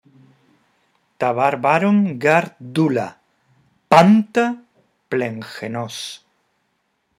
Antes de analizar el fragmento de Los Persas, te proponemos que escuches la lectura de los versos en los que Eurípides habla de la libertad de los griegos.